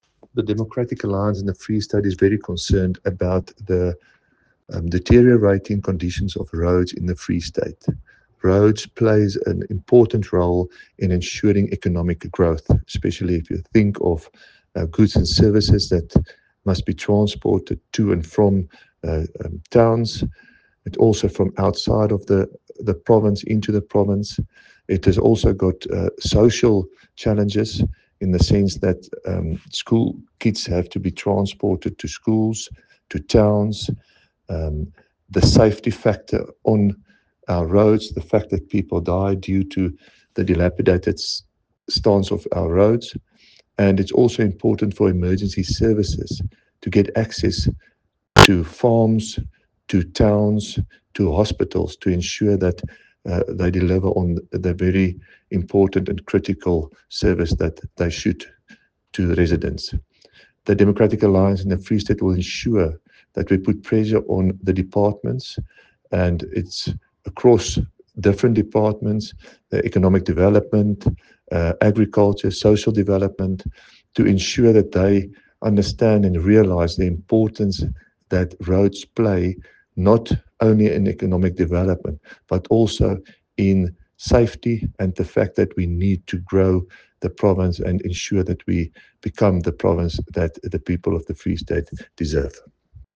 Afrikaans soundbites by David van Vuuren MPL and